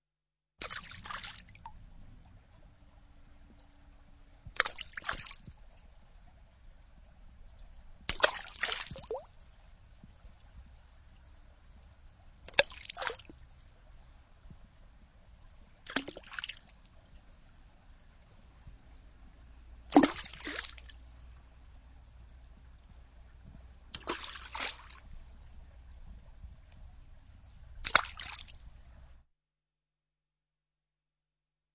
Water droplet.mp3